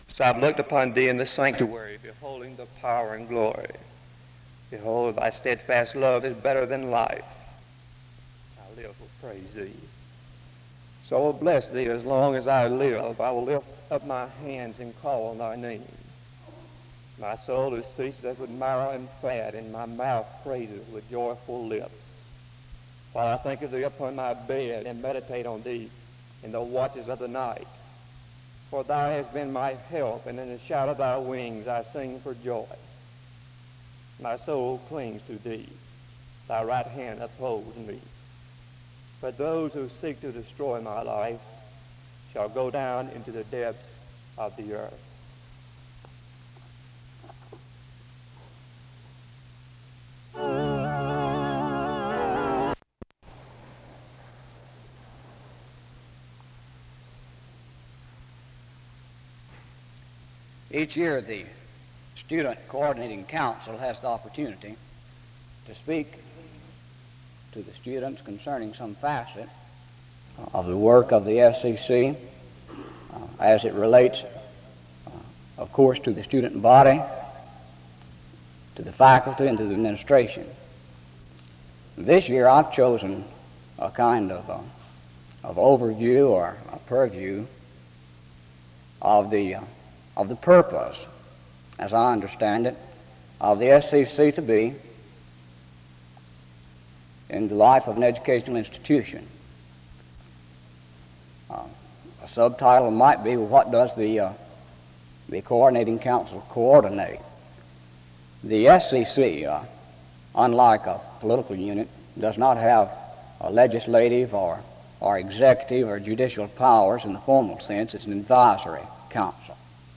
Download .mp3 Description The service begins with the reading of scripture from 0:00-0:47. The structure of the SBC and the Seminary is shared from 1:00-16:40. A closing prayer is offered from 16:52-17:55. This service was organized by the Student Coordinating Council.